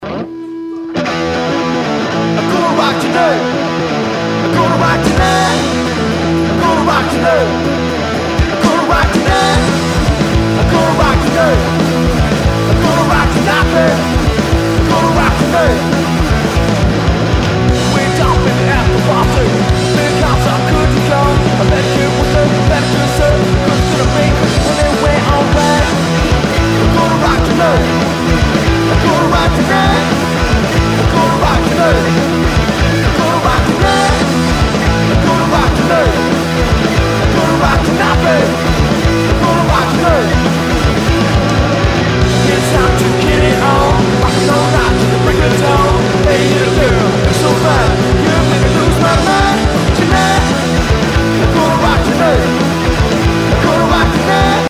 (mono mix)